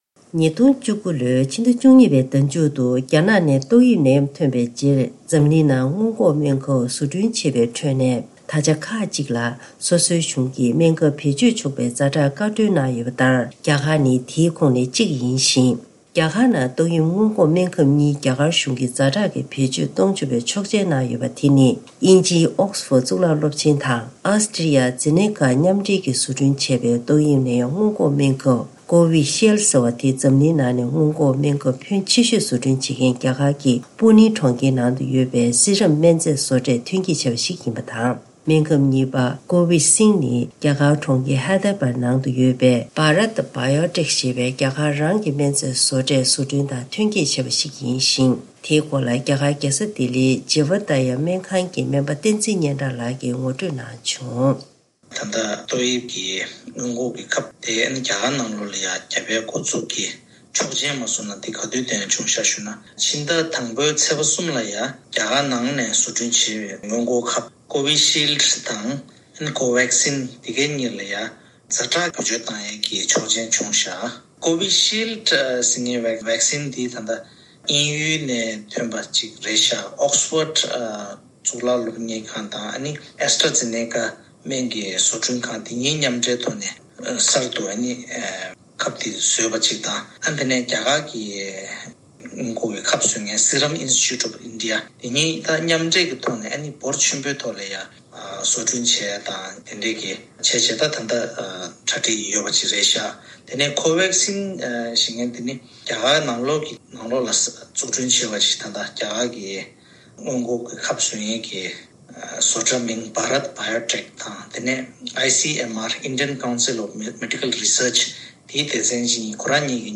བཀའ་འདྲི་ཞུས་ཏེ་ཕྱོགས་བསྒྲིགས་ཞུས་པ་ཞིག་གསན་རོགས་གནང་།